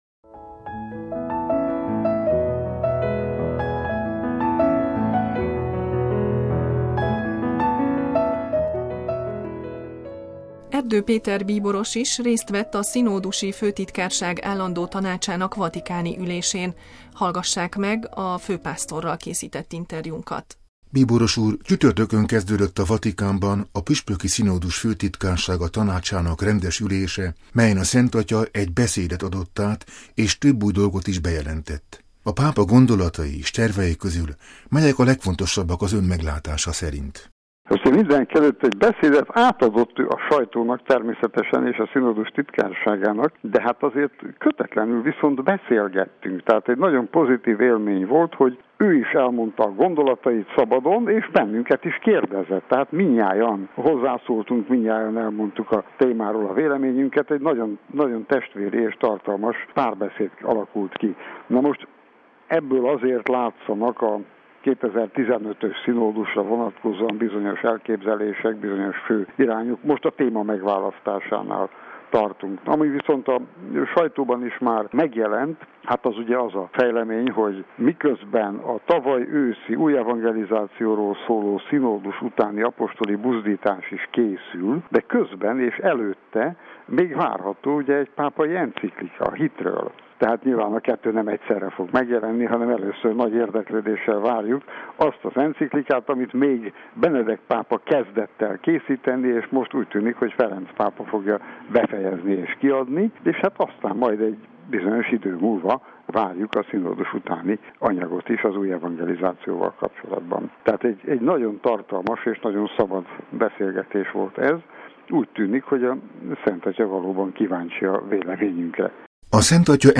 Erdő Péter bíboros műsorunknak adott nyilatkozatában kiemelte Ferenc pápa gondolatai és tervei közül a legfontosabbakat.
Az interjúban az esztergom-budapesti bíboros érsek válaszol arra a kérdésre is, hogy hogyan értelmezhető ez a kapcsolat.